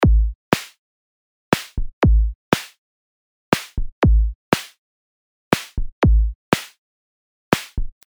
Wir kommen zu den Klangparametern, die man innerhalb der Sequenz eintragen kann, am Beispiel eines Trios aus zwei Bassdrums und einer Snare.
Das Pattern hört sich so an: